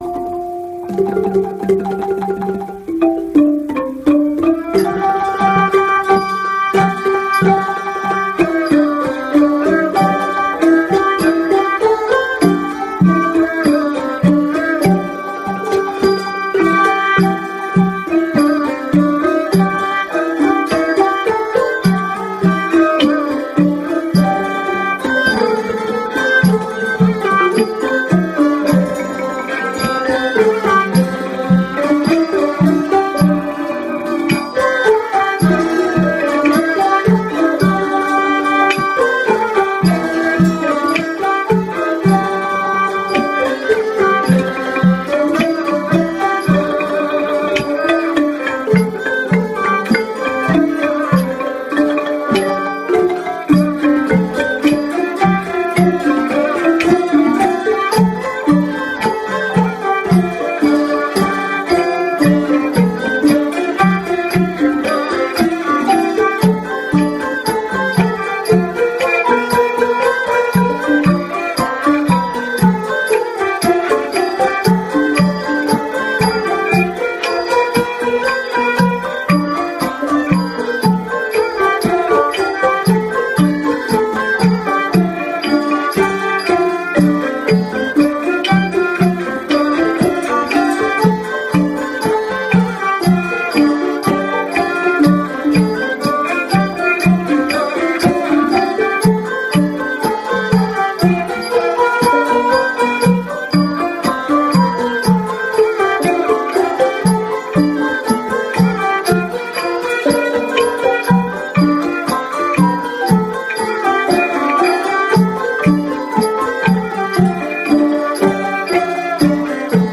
ເຊີນຟັງການເສບດົນຕີພື້ນເມືອງລາວ ຂອງຄະນະເຍົາວະຊົນ ລາວ-ອາເມຣິກັນ ທີ່ນະຄອນຊິອາເຕີນ